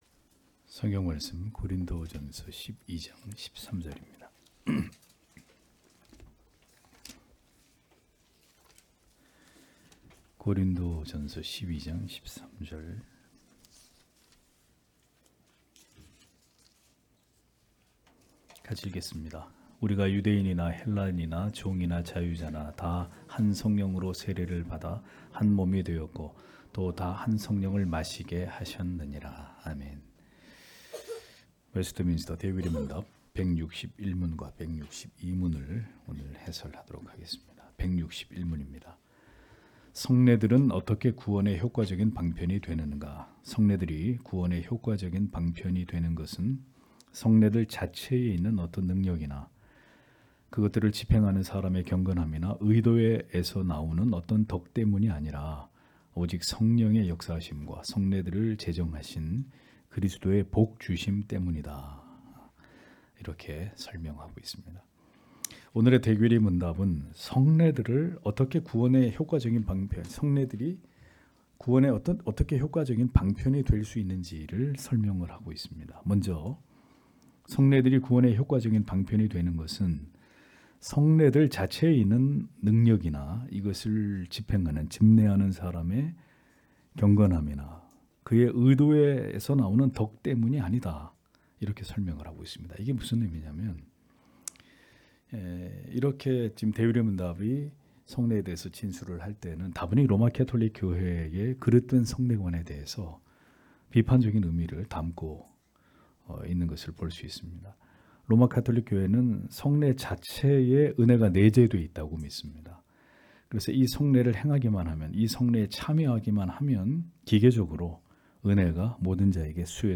주일오후예배 - [웨스트민스터 대요리문답 해설 161-162 ] 161문) 성례가 어떻게 구원의 유효한 방편이 되는가 162문) 성례란 무엇인가?